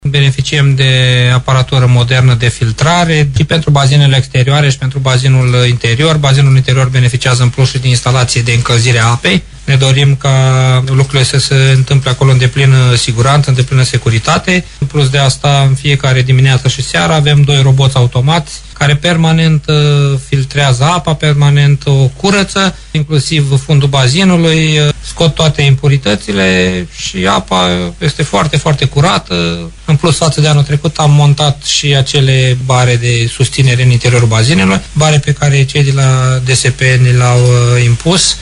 Prezent în studioul VIVA FM, primarul CĂTĂLIN COMAN i-a invitat pe toți amatorii de mișcare să vină în zona iazului Șomuz.